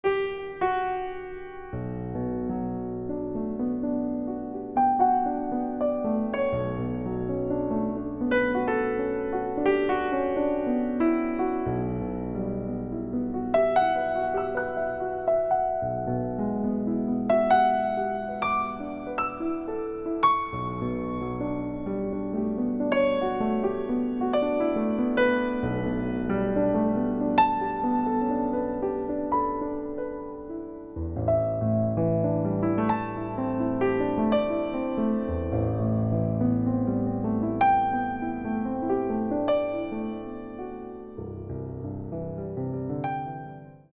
Каталог -> Джаз та навколо -> Інше близьке